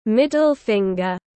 Ngón giữa tiếng anh gọi là middle finger, phiên âm tiếng anh đọc là /ˌmɪd.əl ˈfɪŋ.ɡər/.